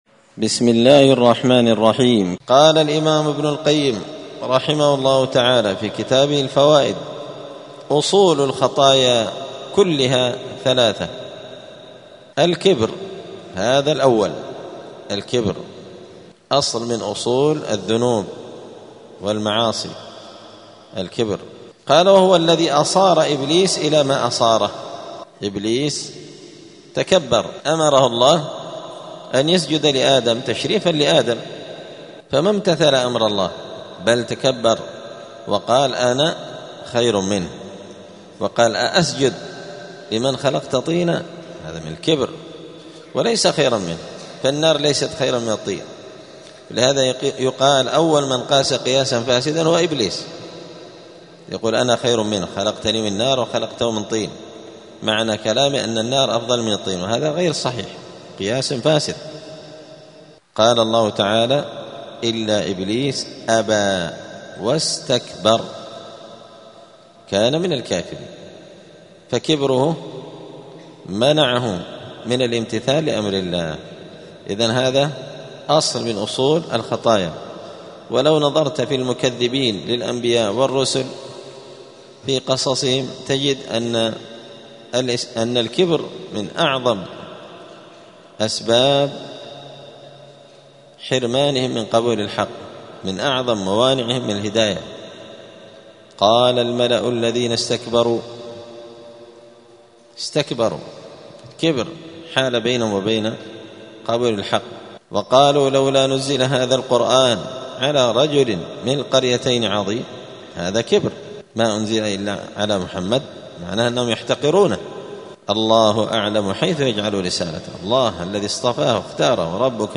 *الدرس الحادي والثلاثون (31) {فصل: أصول الخطايا}*